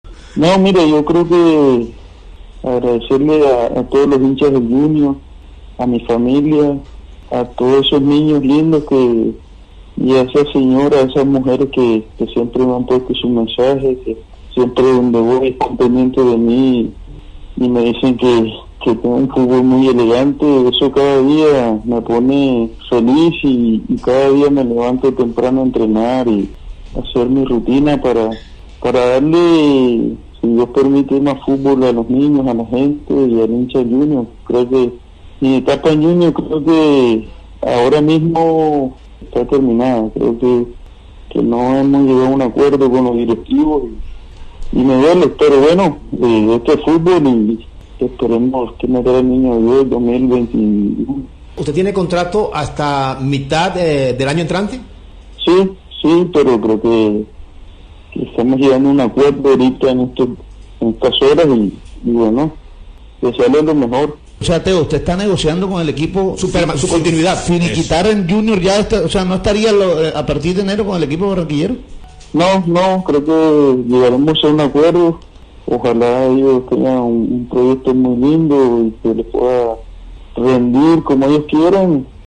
(Teófilo Gutiérrez en diálogo con Deporte Espectacular de Barranquilla)